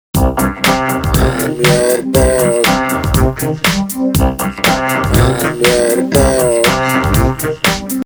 Tag: 120 bpm Hip Hop Loops Groove Loops 1.35 MB wav Key : Unknown